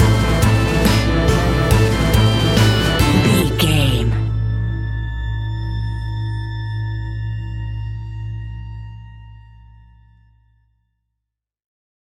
Aeolian/Minor
ominous
dark
suspense
eerie
electric organ
strings
acoustic guitar
harp
synthesiser
drums
percussion
spooky
horror music